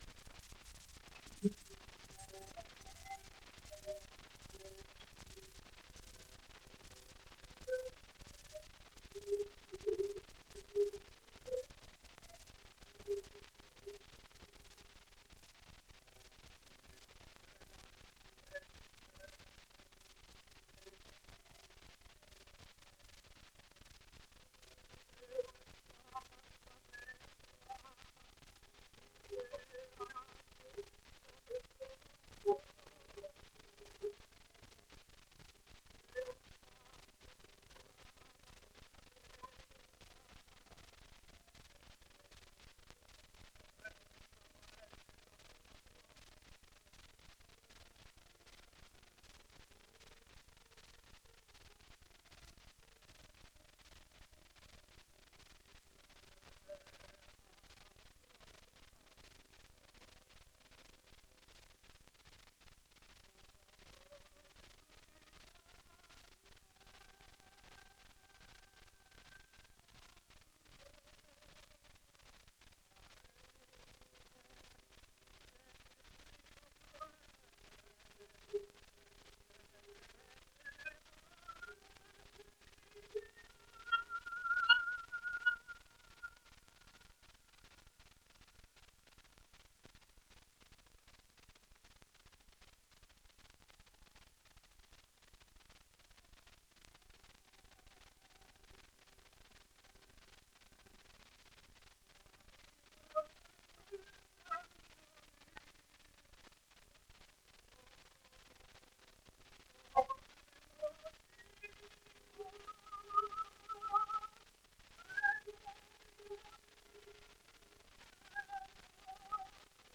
La Tosca: E lucean le stelle (sonido remasterizado)